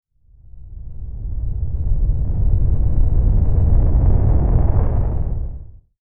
環境音 | 無料 BGM・効果音のフリー音源素材 | Springin’ Sound Stock
地響き1短.mp3